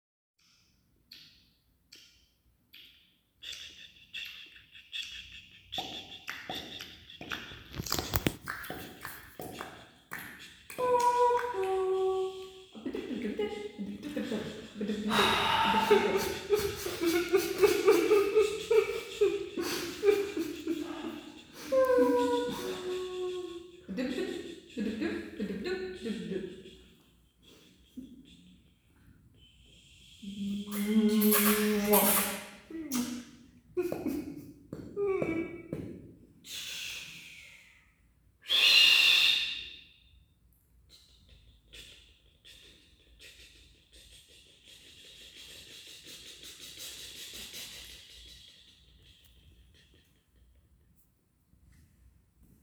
Zabawa z dźwiękami
Grupa zaawansowana oraz młodzieżowa Teatru Szóstka wykonały ćwiczenia polegające na odtworzeniu danego tematu jedynie za pomocą wydawanych przez siebie dźwięków. Dodatkowym utrudnieniem było to że można było używać wyłącznie dźwięków wydawanych własnym głosem.